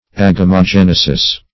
Agamogenesis \Ag`a*mo*gen"e*sis\, n. [Gr.